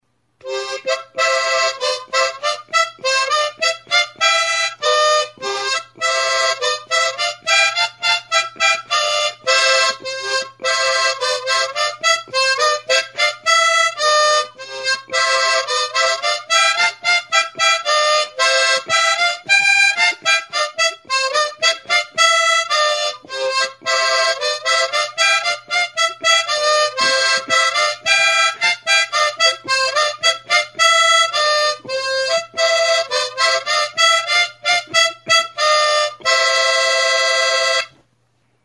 Enregistré avec cet instrument de musique.
AHO-SOINUA; EZPAINETAKO SOINUA; XOTIXE; HARMONICA